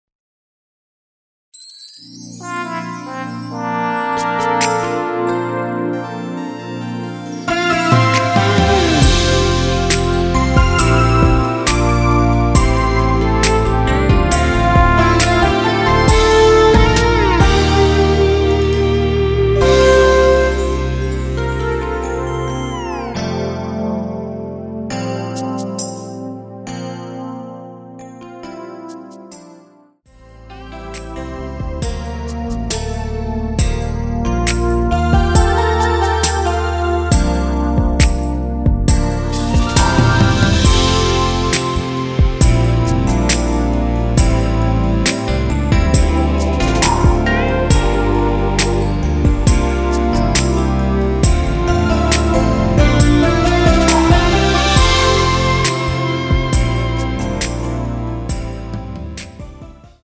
원키에서(-2)내린(짧은편곡) MR입니다.
앞부분30초, 뒷부분30초씩 편집해서 올려 드리고 있습니다.